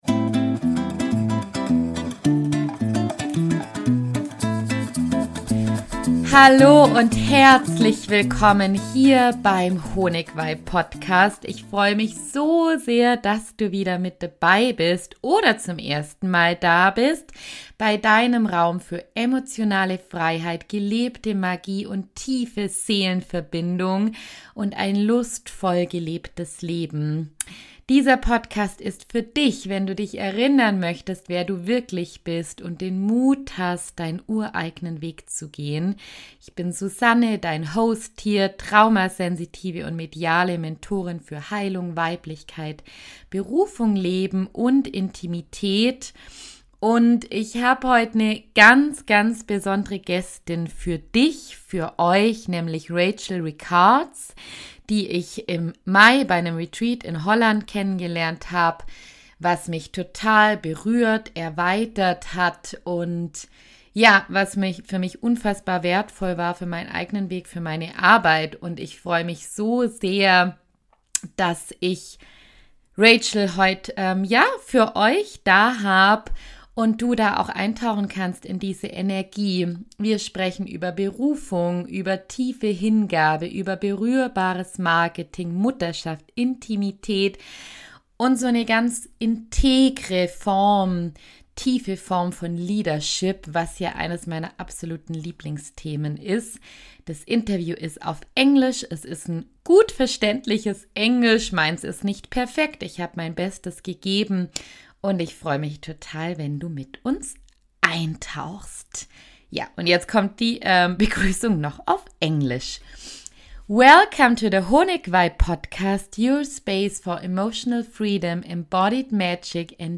We talk about: What devoted, embodied leadership really means How devotion to one’s purpose can guide the way Leading groups as an introvert – and why sensitivity is a gift The role of vulnerability and intimacy in leadership & Social Media the intersection of motherhood and leading from presence How "The Field" Training came to life and why it's so special and how we can gently reopen to intimacy after pain or contraction A grounded, honest and deeply inspiring and touching conversation about leading from within – with presence, truth, and devotion.